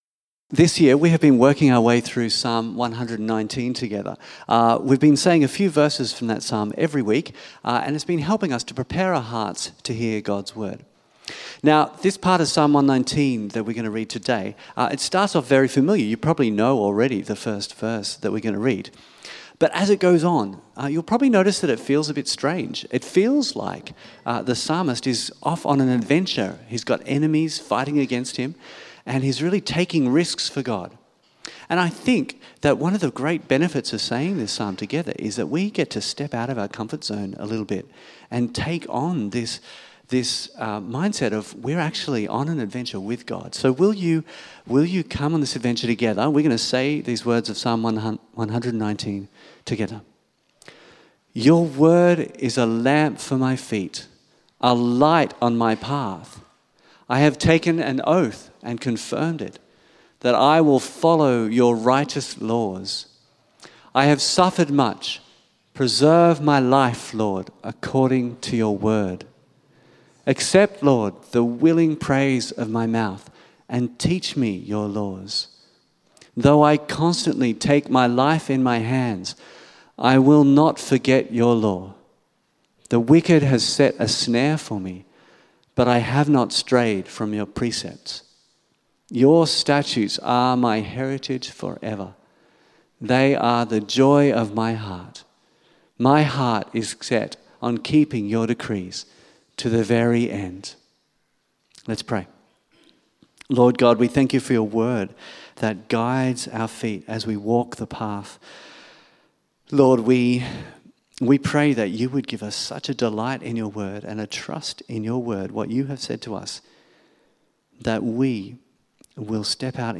Meet Jesus Sermon outline